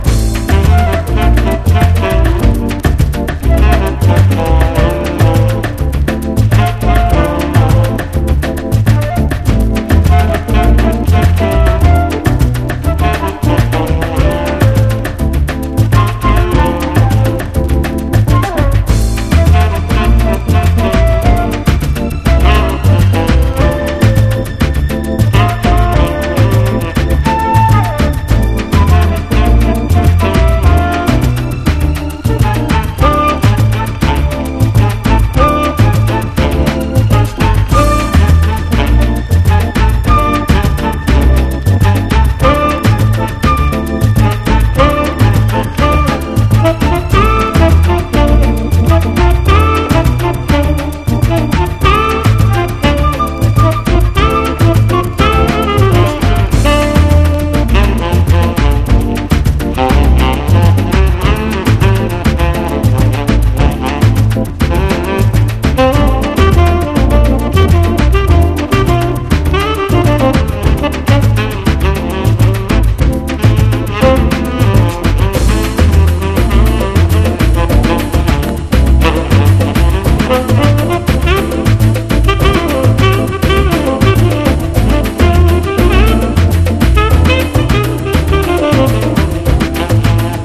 BREAKBEATS/HOUSE / POST PUNK DISCO